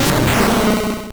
Cri de Tyranocif dans Pokémon Or et Argent.